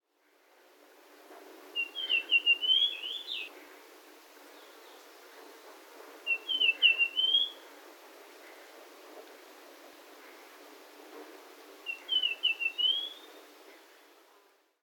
今回もきつい峠越えの道を歩いている時に澄んだ声で「キキ キコーキー　キキ キーコーキー」と鳴いてくれた。
【録音④】 イカル